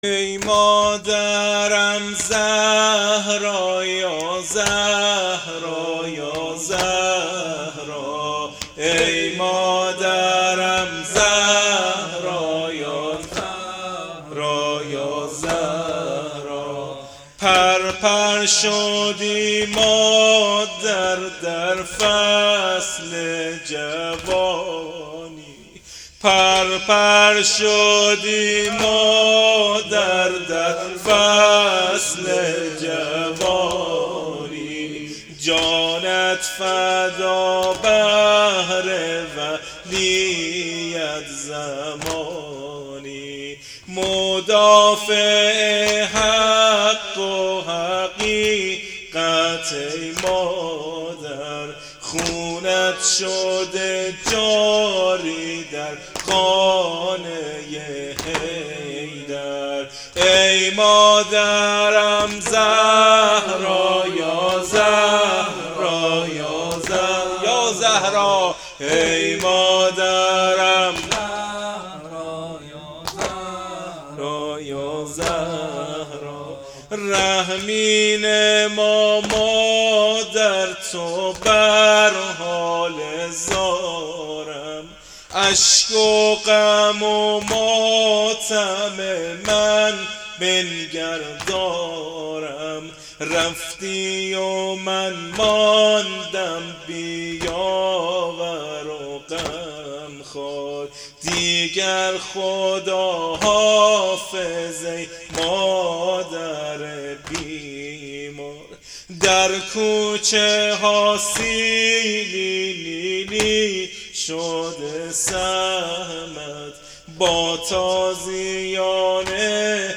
نوحه شهادت حضرت زهرا س